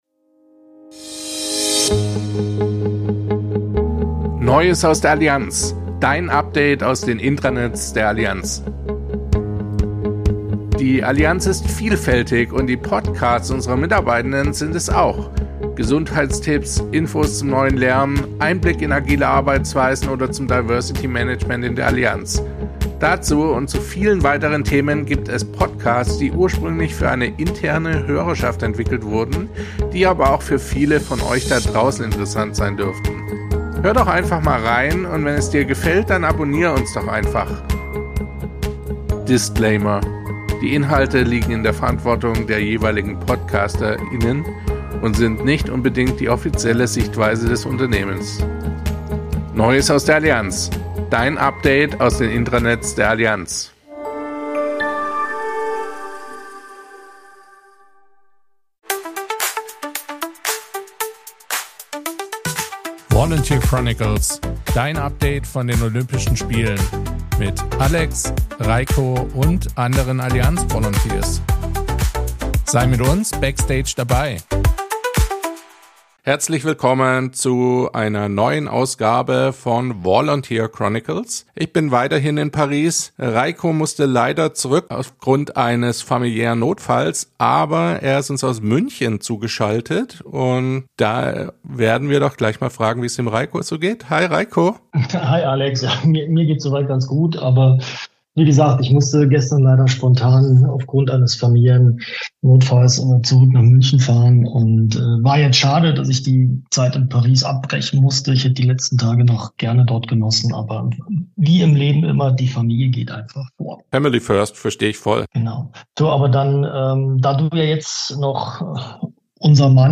Daher podcasten wir heute aus München und Paris. In dieser Folge geht es um den Einzug der deutschen Hockeyherren ins olympische Finale, um das Gelände rund um das Hockeystadion von Yves du Manoir und darum, wie die Olympischen Spiele auch die Allianz Sports inspirieren könnten.